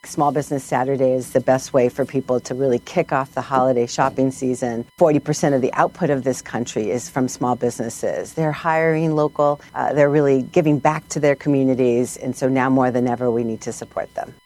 According to Isabelle Guzman, Administrator for the Small Business Administration, nearly half of the country’s output is from small businesses…